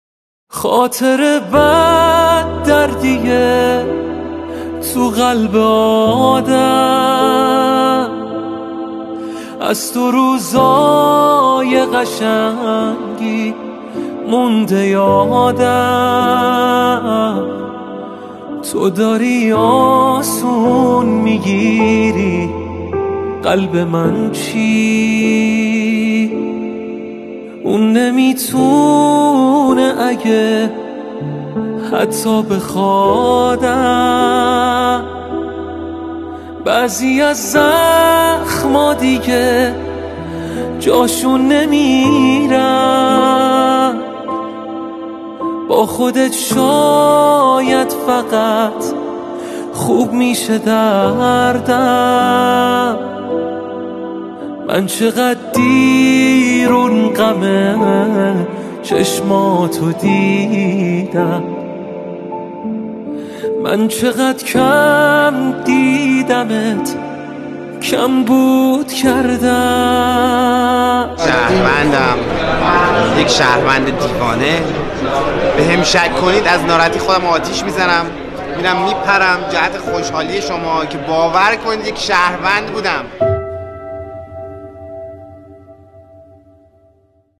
دانلود آهنگ پاپ